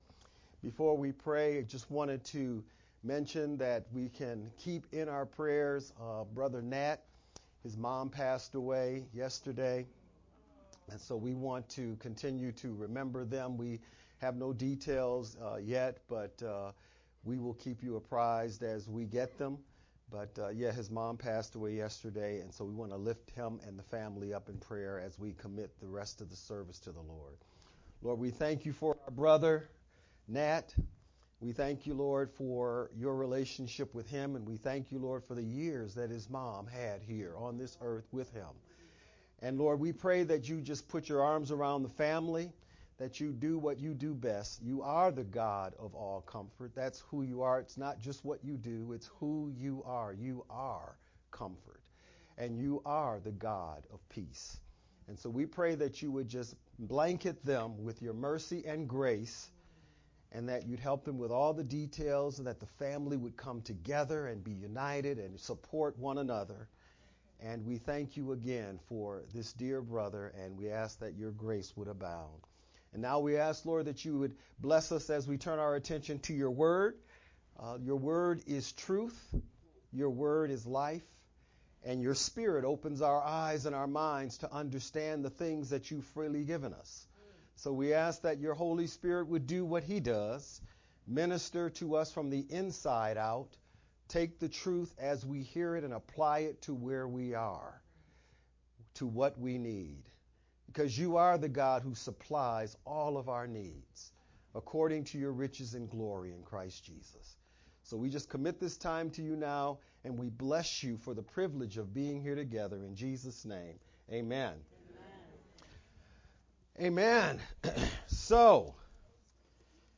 March-1st-VBCC-sermon-edited-CD.mp3